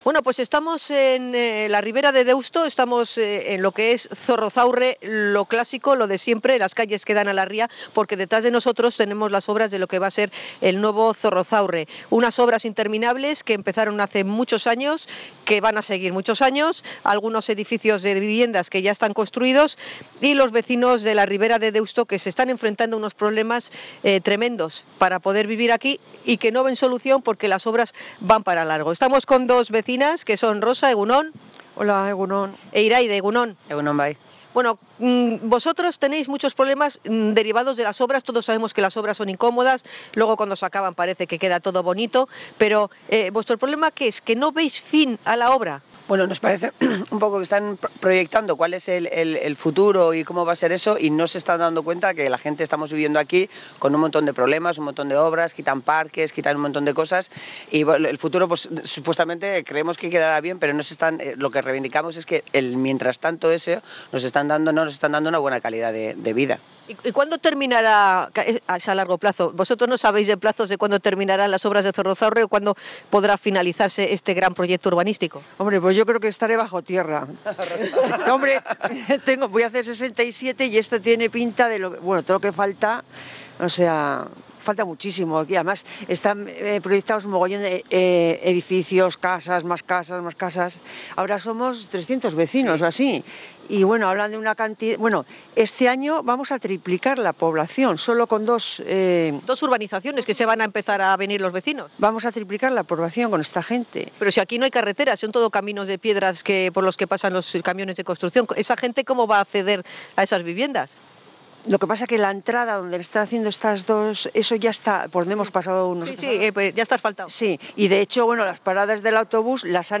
INT.-VECINOS-RIBERA-DE-DEUSTO.mp3